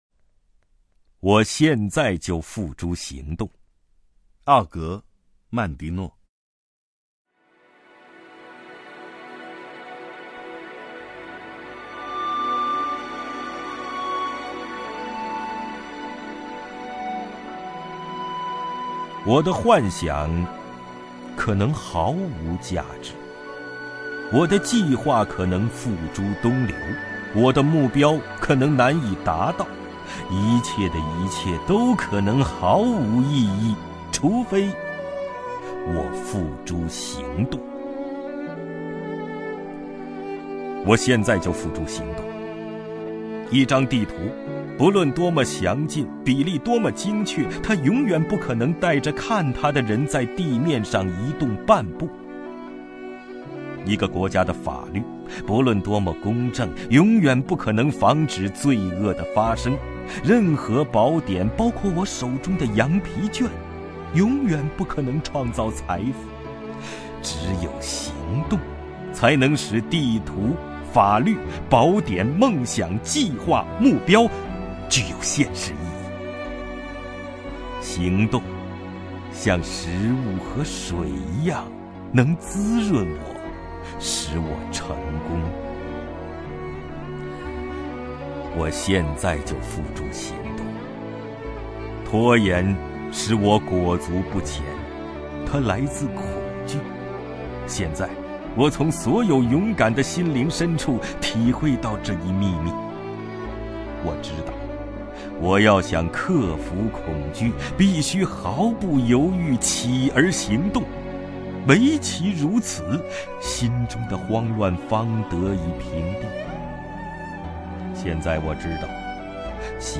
王凯朗诵：《我现在就付诸行动》(（美）奥格·曼狄诺)　/ （美）奥格·曼狄诺
名家朗诵欣赏 王凯 目录